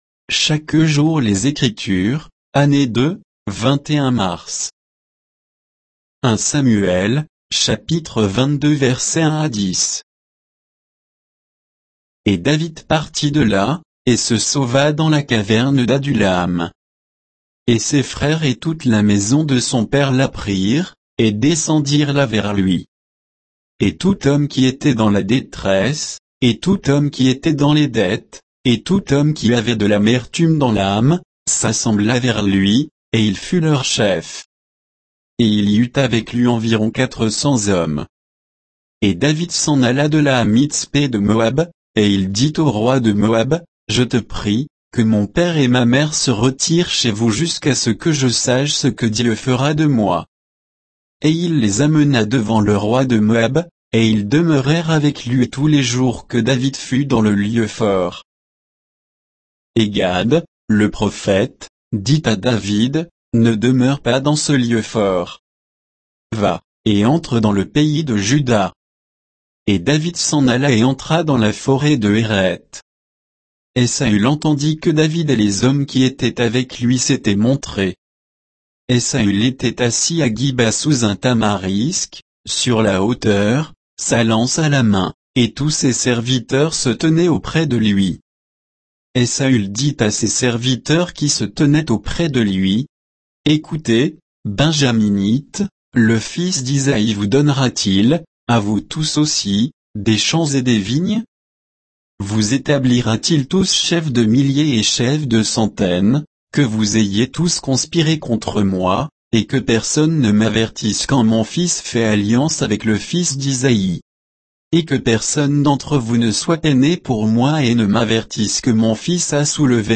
Méditation quoditienne de Chaque jour les Écritures sur 1 Samuel 22